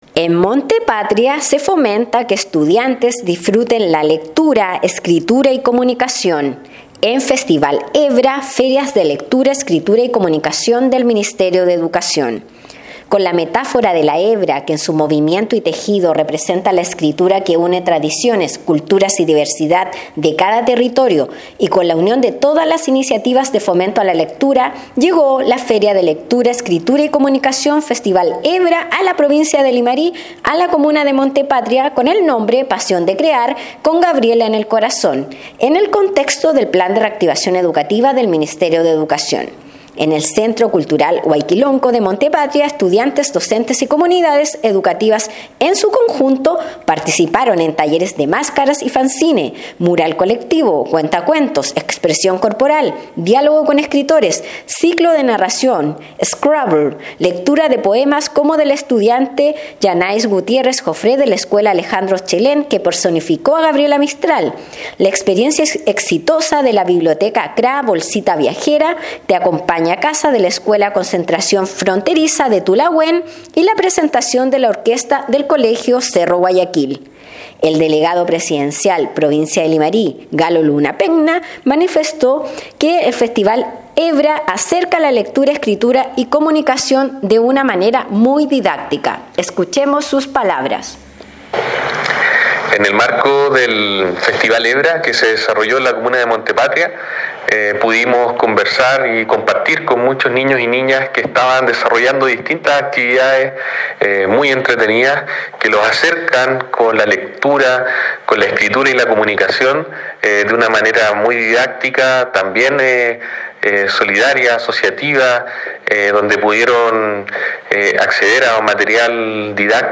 Despacho-Radial-En-Monte-Patria-se-fomenta-que-estudiantes-disfruten-la-lectura-escritura-y-comunicacion_.mp3